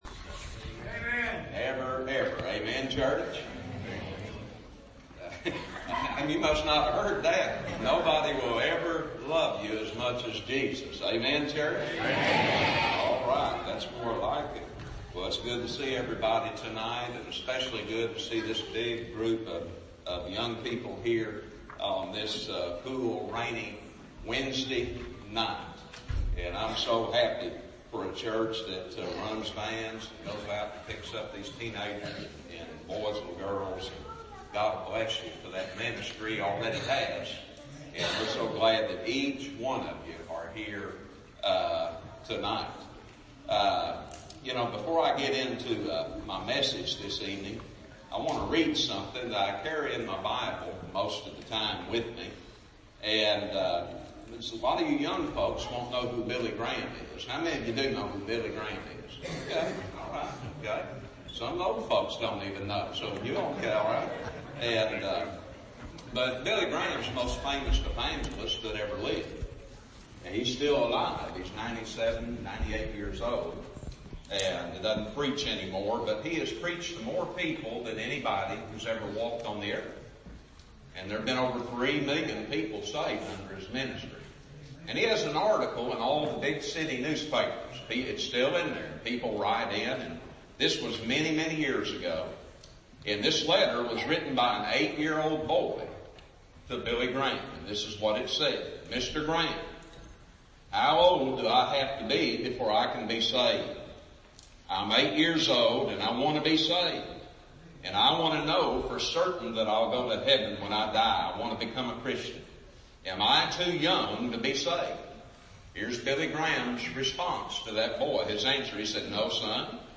Revival Message Wednesday Evening » Clear Fork Baptist Church